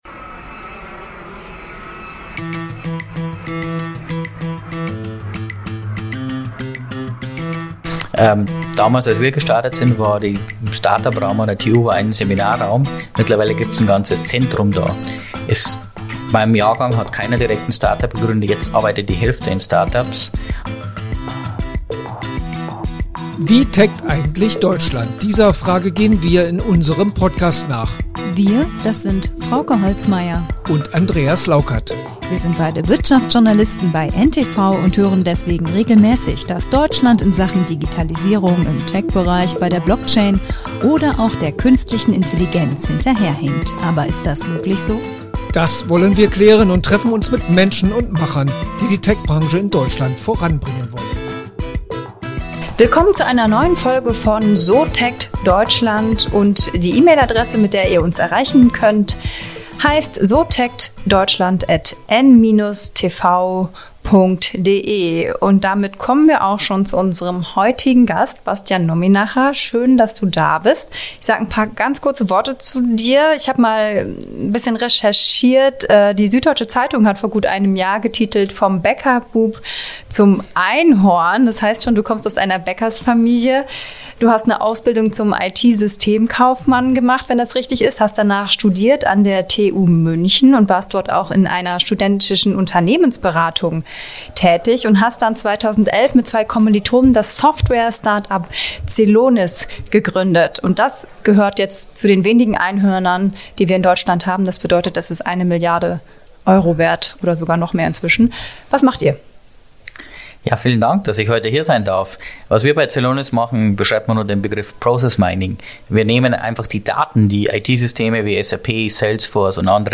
ganz trocken im oberbayerischen Akzent